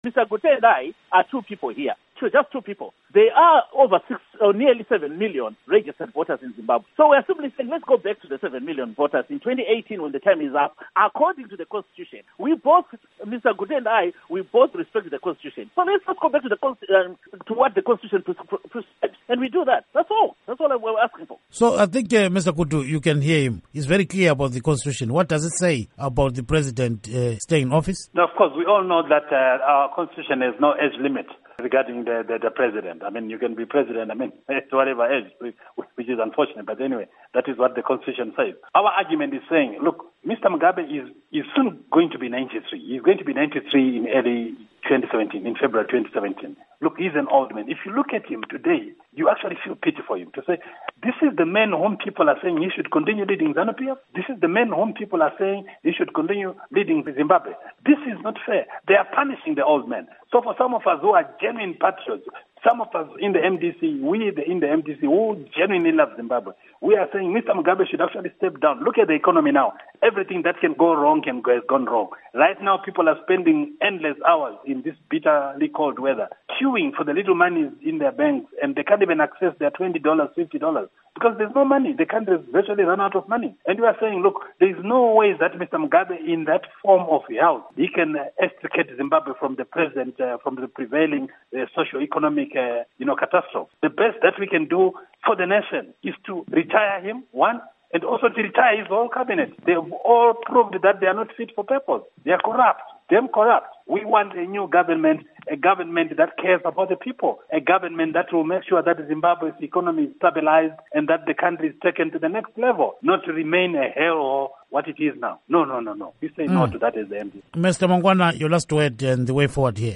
Interview With Obert Gutu and Nick Mangwana